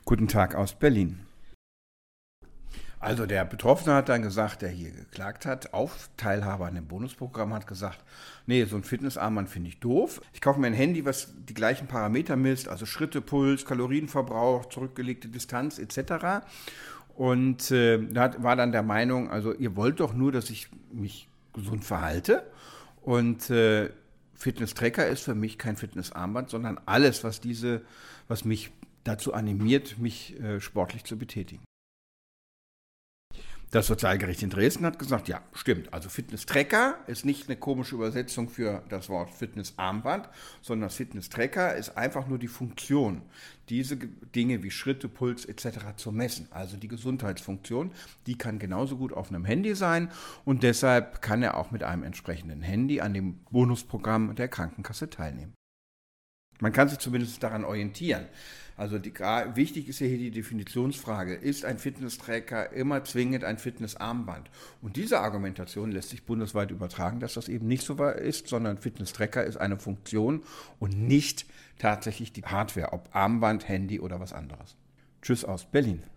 Kollegengespräch: Krankenkasse muss statt Fitnesstracker auch Handy bezahlen